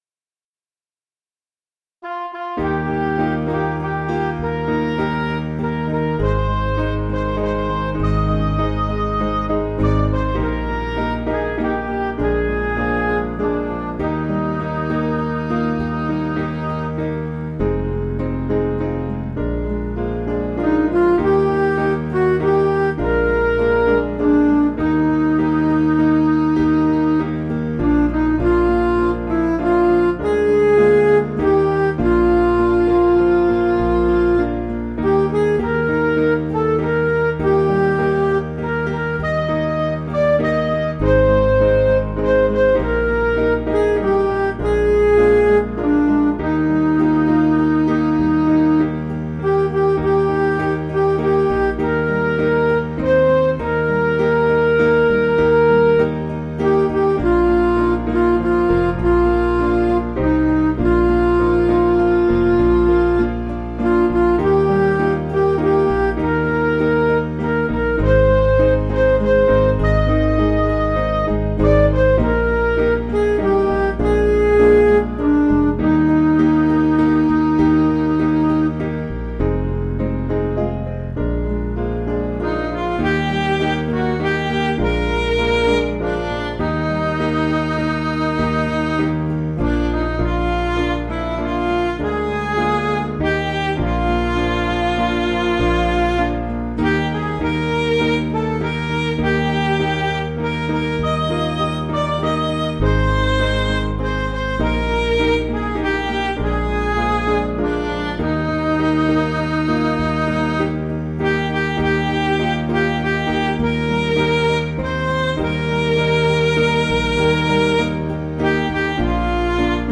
Piano & Instrumental
(CM)   3/Eb